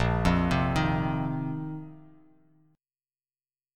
A#sus2sus4 Chord